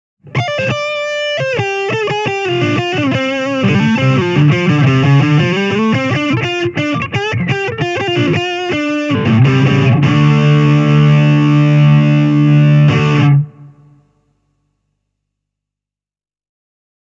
Humbuckereilla soitettuna astuu jo tässä vaiheessa kuvaan uutuuspedaalin kermainen kompressointi (Hamer, Dist klo 9):